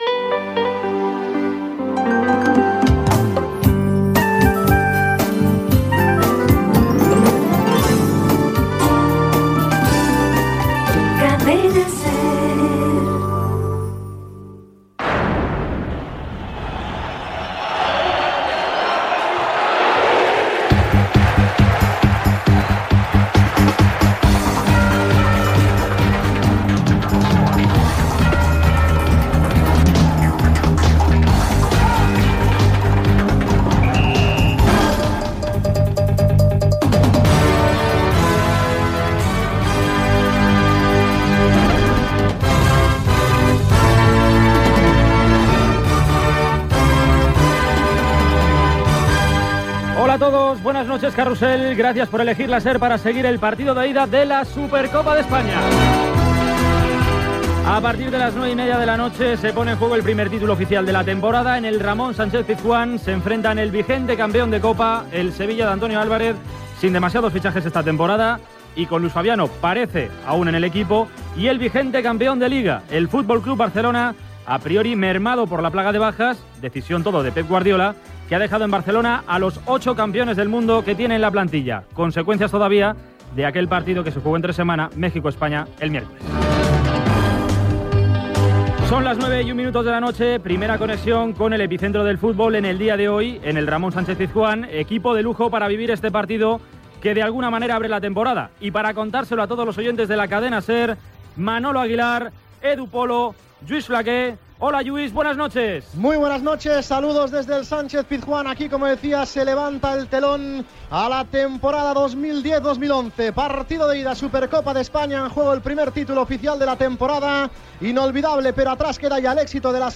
Indicatiu, sintonia del programa i salutació del primer "Carrusel deportivo "sense l'equip de Paco González i Pepe Domingo Castaño, que havien fitxat per la Cadena COPE. Connexió amb el Sánchez Pizjuán de Sevilla per narrar el Sevilla-Futbol Club Barcelona
Esportiu